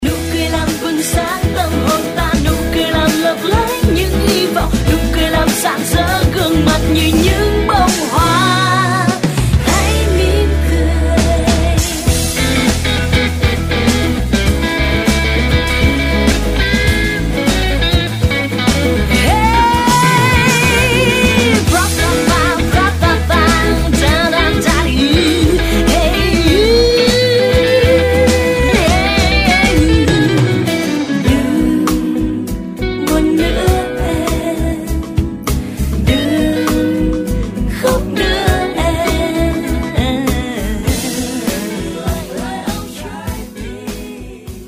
Pop/ Acoustic/ Indie